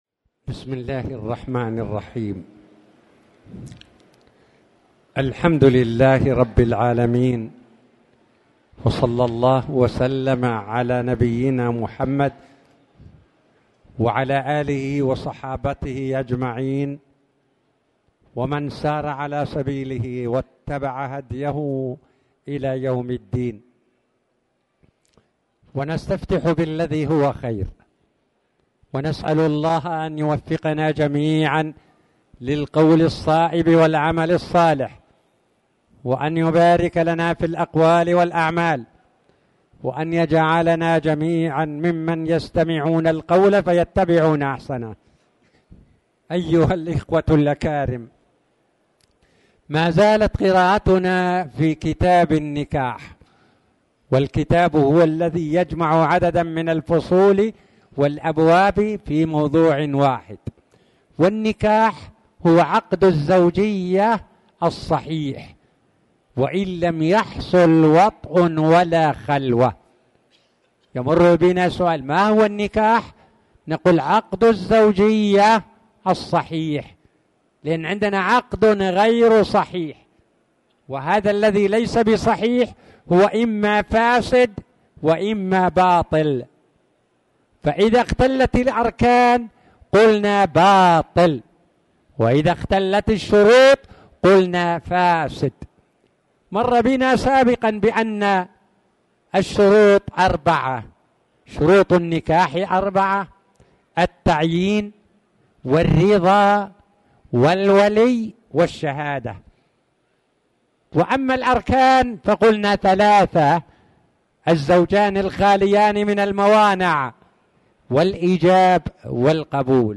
تاريخ النشر ٩ صفر ١٤٣٨ هـ المكان: المسجد الحرام الشيخ